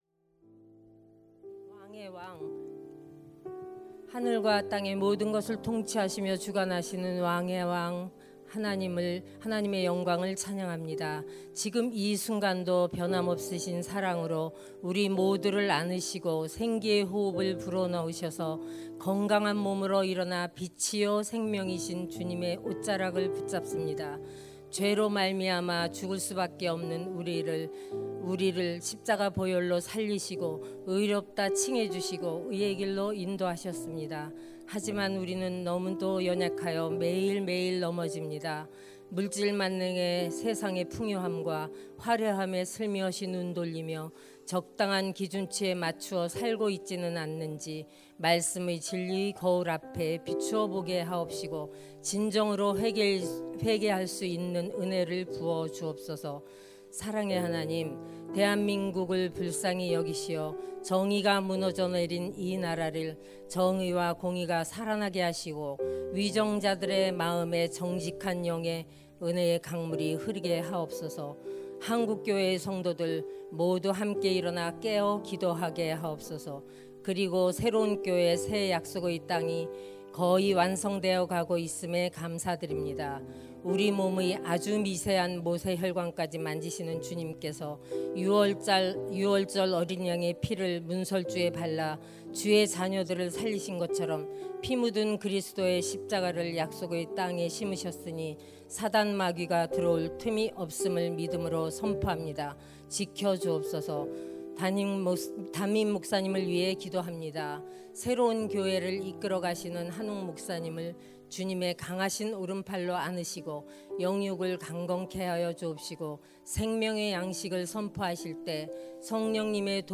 2026-04-10 공동체 주관 새벽기도회
> 설교